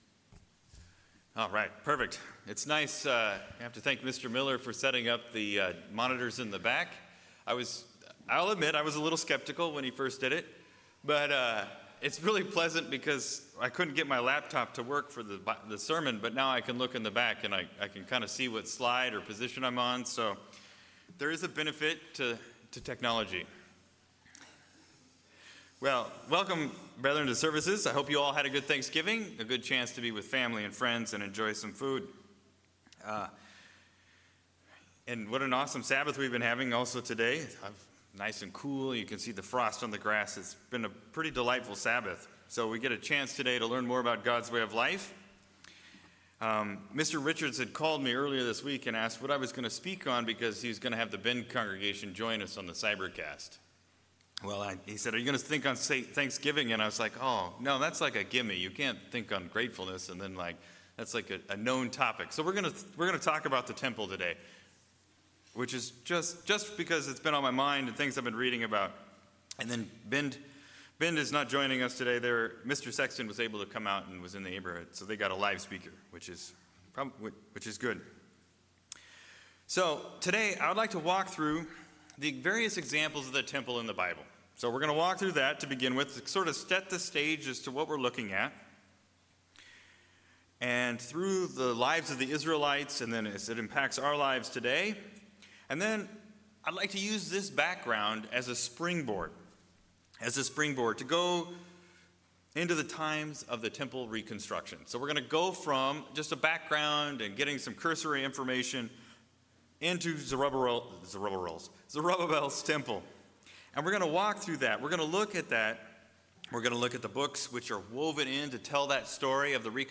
Given in Salem, OR